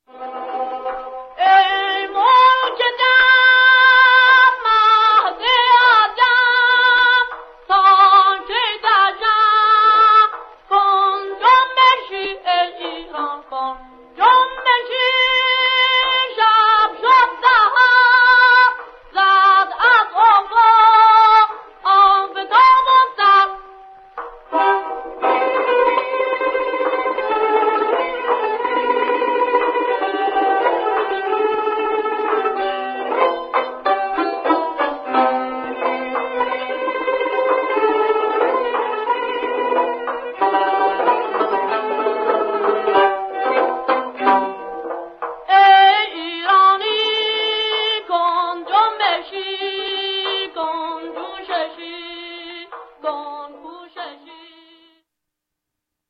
تار
ویلن
این ارکستر ۴ نفره
سرود ملی در ماهور